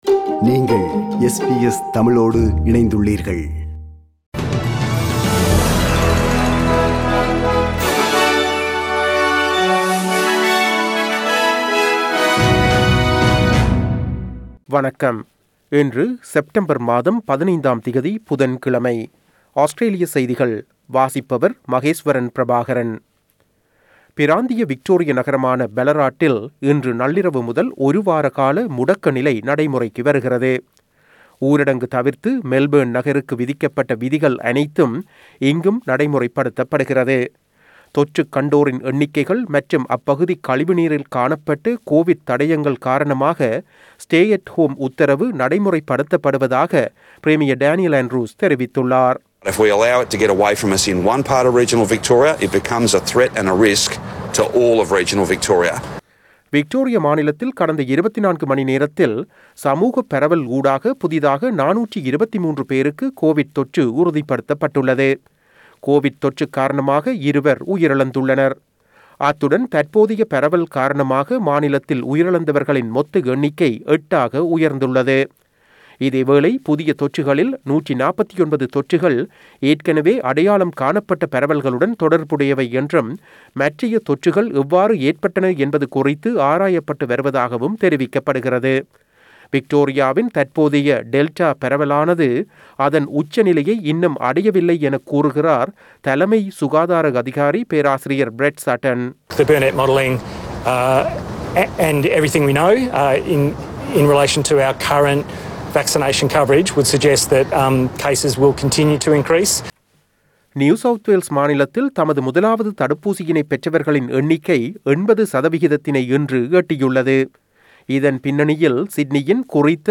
Australian news bulletin for Wednesday 15 September 2021.